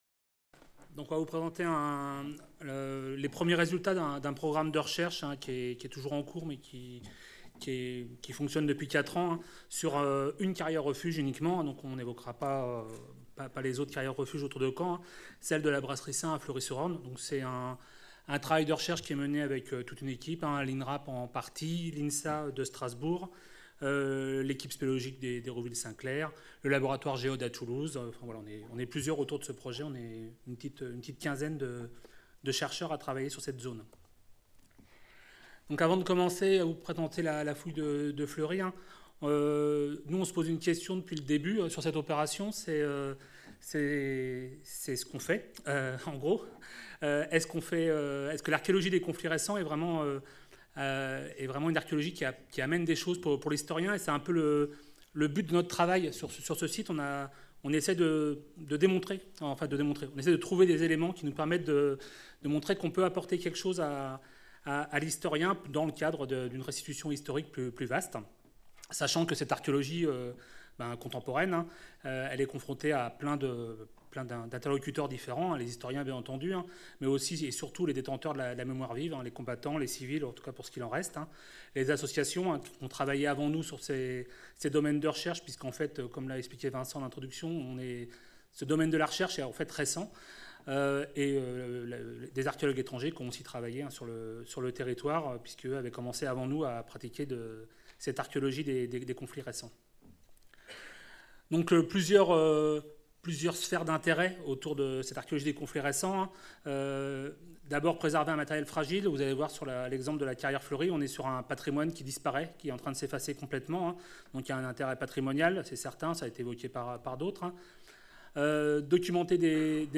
Cette communication a été filmée lors du colloque international intitulé De Verdun à Caen - L'archéologie des conflits contemporains : méthodes, apports, enjeux qui s'est déroulé au Mémorial de Caen les 27 et 28 mars 2019, organisé par la DRAC Normandie, la DRAC Grand-Est, l'Inrap et l'Université de Caen (MRSH-HisTeMé) avec le partenariat de la Région Normandie, du Département du Calvados, de la Ville de Caen et du Groupe de recherches archéologiques du Cotentin. Depuis plus d’une décennie, l’archéologie contemporaine s’est approprié ce nouvel objet d’étude que sont les conflits de l’ère contemporaine.